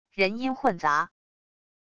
人音混杂wav音频